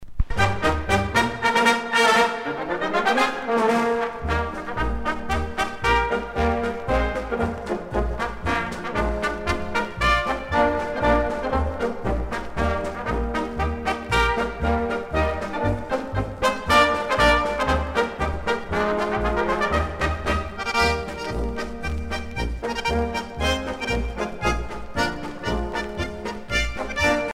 danse : marche ; danse : marche-polka ;
Pièce musicale éditée